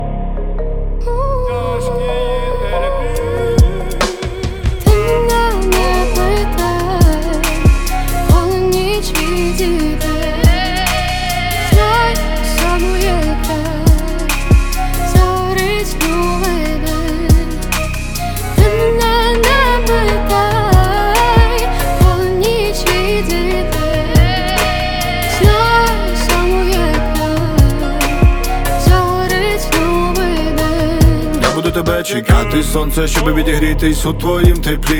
Жанр: Рэп и хип-хоп / Украинские